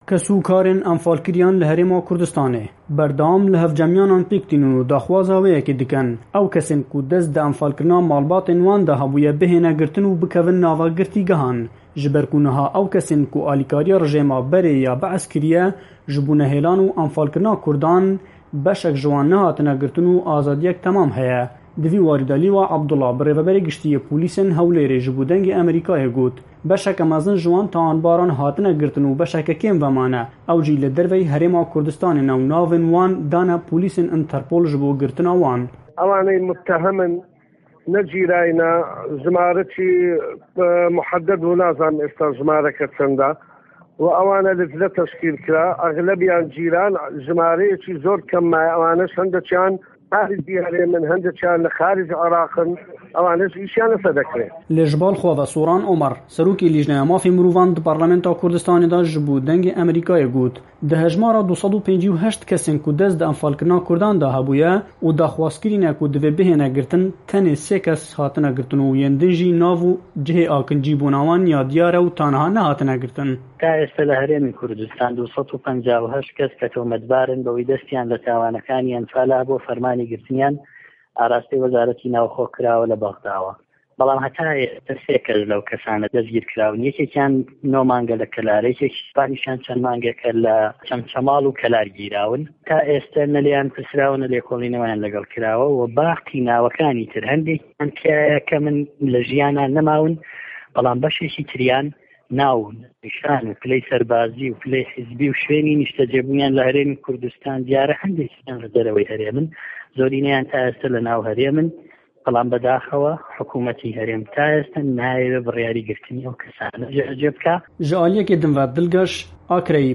لیوا عه‌بدوڵڵا به‌ڕێوه‌به‌ری گشـتی پـۆلیسی هه‌ولێر به‌ ده‌نگی ئه‌مه‌ریکای گوت به‌شێـکی زۆری ئه‌و تاوانکارانه‌ ده‌سـتگیرکراون و به‌شێـکی که‌میان ماوه‌ته‌وه‌ که‌ له‌ ده‌ره‌وه‌ی هه‌رێمی کوردسـتانن و ناوه‌کانیان دراونه‌ته‌ پـۆلیسی نێوده‌وڵه‌تی(ئنته‌رپـول).